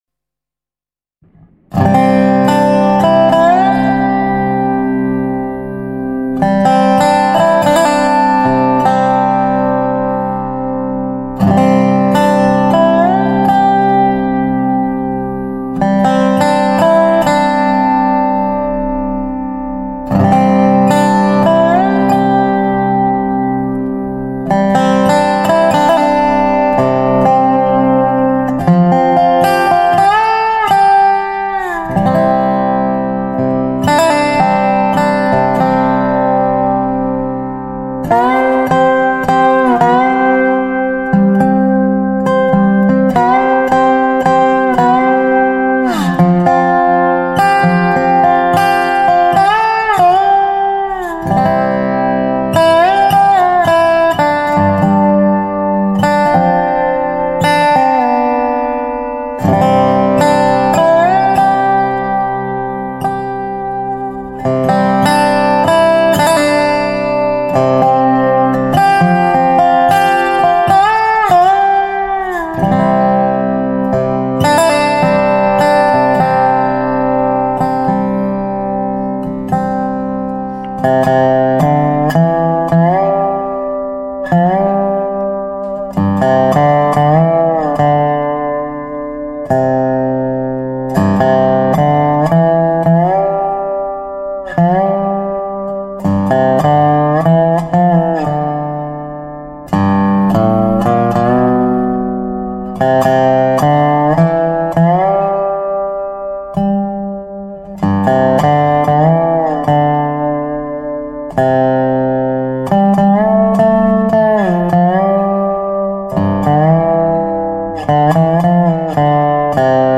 I must tell Jesus on Meredith mahogany/cedar resonator guitar